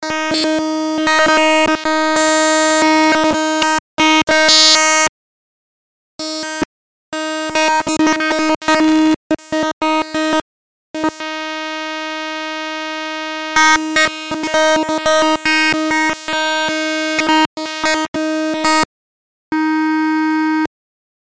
I took the LOCK audio, separated the right channel and sped it up 77 times. Behold, this is the GSM-type sound that was just discussed:
OUS_LOCK_SPED_RIGHT.mp3